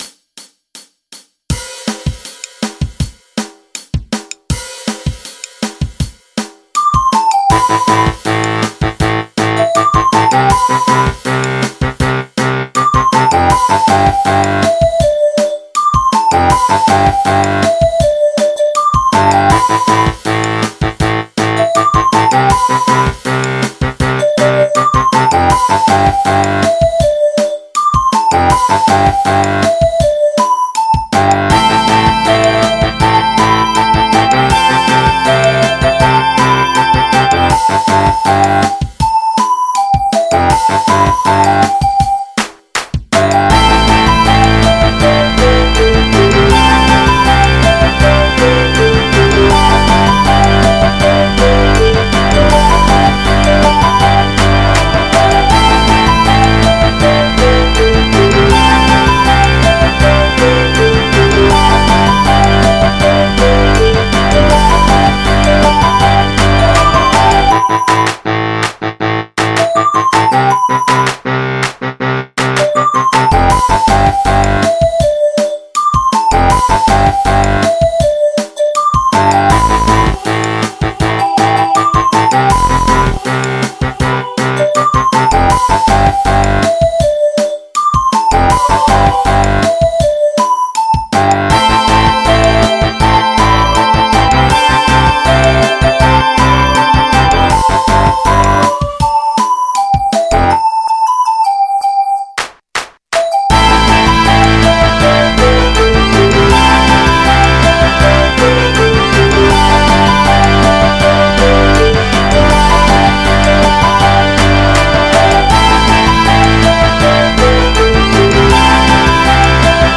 MIDI 39.6 KB MP3 (Converted)